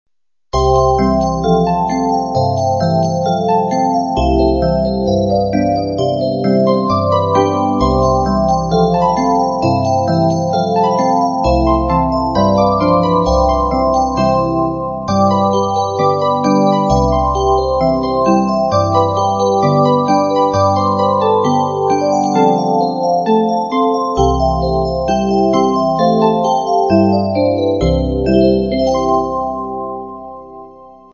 [Computer Simulation]
having a Polyphon Mechanism.
[Simulations may not be pitched to your Music Box.]